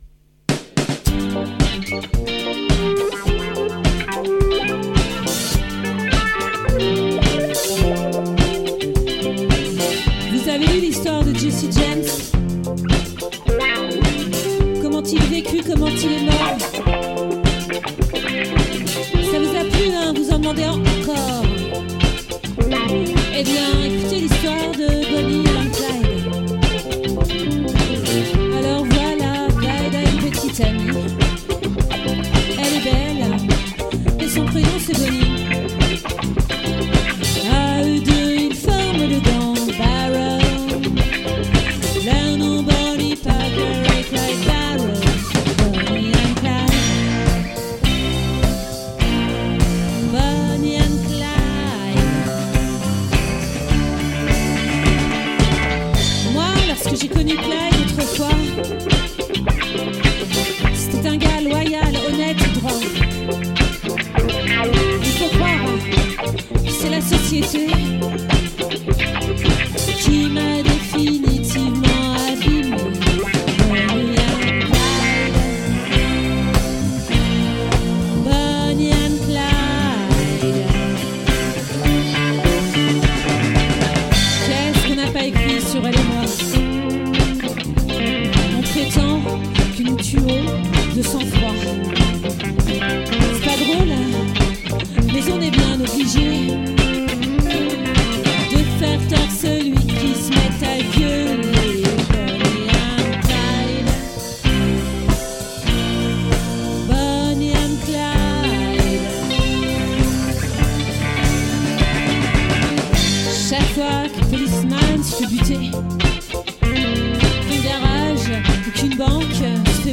🏠 Accueil Repetitions Records_2022_10_05_OLVRE